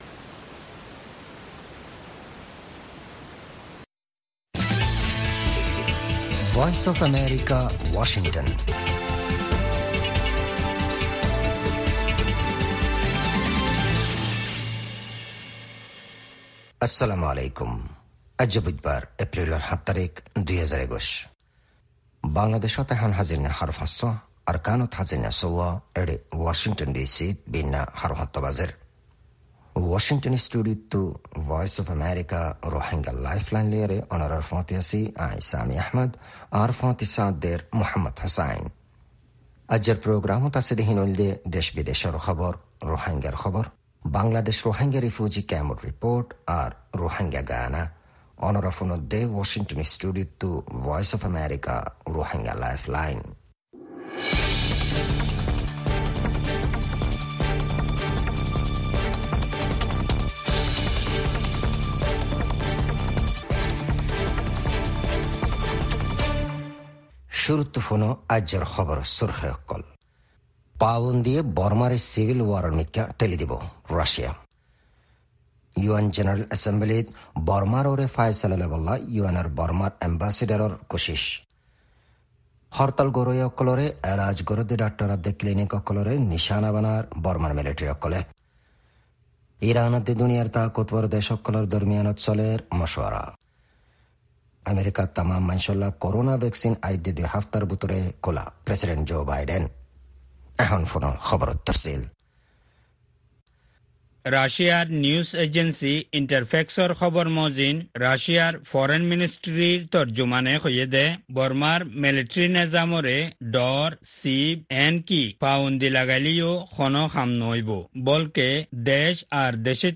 Rohingya “Lifeline” radio